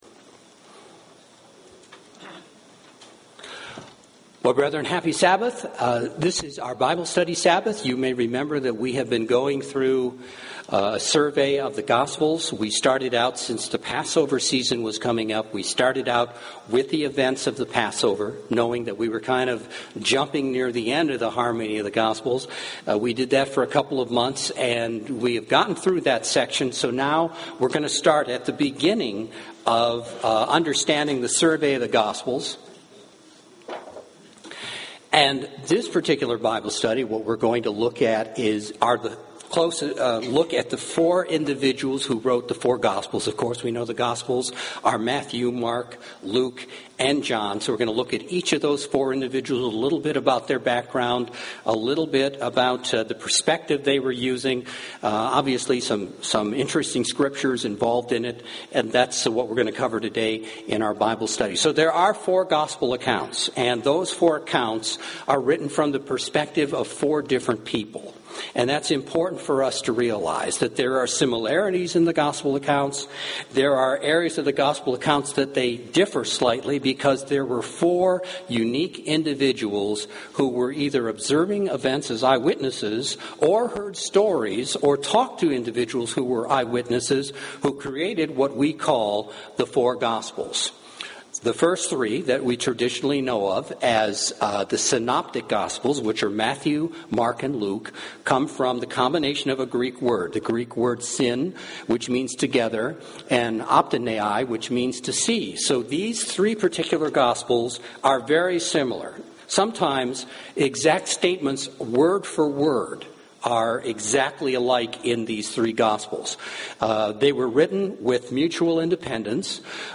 In this Bible study, we review of the authors of the Synoptic Gospels Matthew, Mark, Luke - and the Gospel of John.